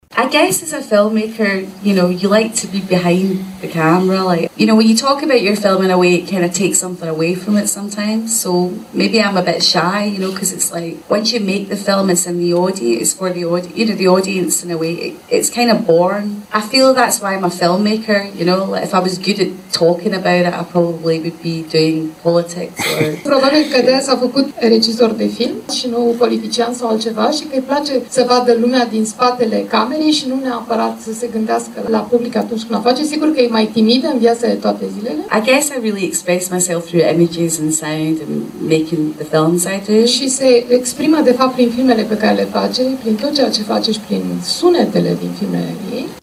Lynne Ramsay, considerată una dintre cele mai îndrăznețe regizoare britanice ale cinemaului independent contemporan, s-a aflat la Braşov la proiecția filmului „You Were Never Really Here”, la prima ediţie Les Films de Cannes a Brașov, eveniment la care Radio Tg. Mureș este partener media. Regizoarea Lynn Ramsay a răspuns întrebărilor publicului spectator, ajutată de criticul de film Irina Margareta Nistor: